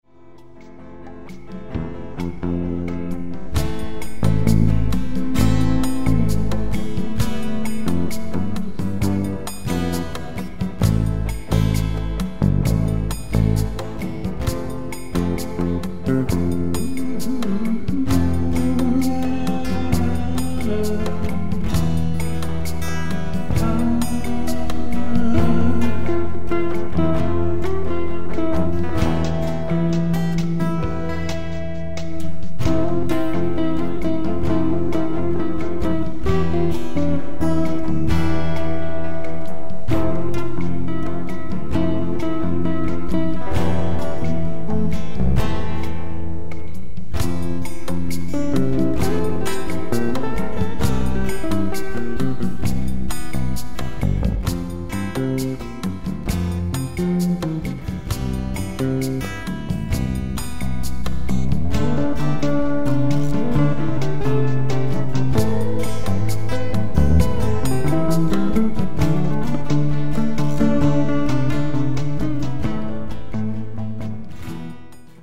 Sie sind zum träumen und entspannen gedacht.